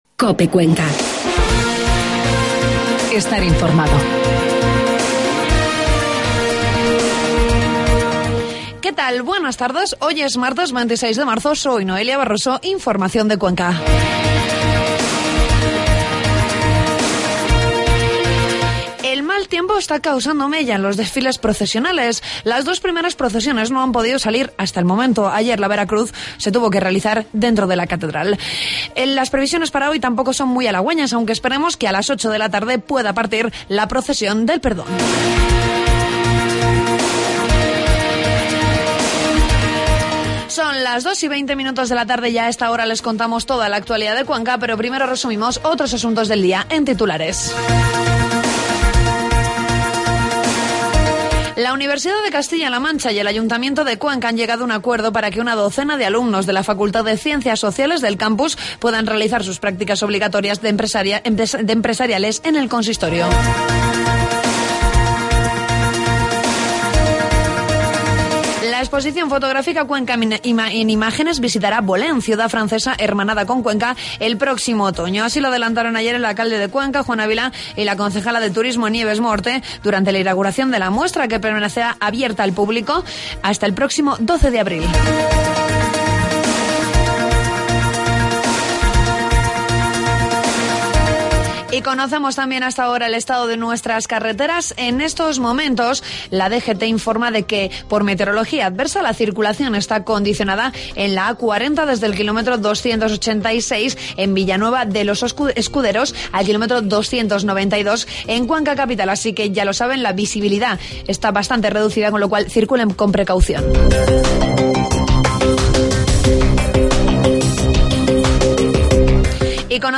Toda la información de la provincia de Cuenca en los informativos de mediodía de COPE